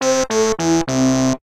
compiler-failure.ogg